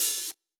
Closed Hats
Hat (11).wav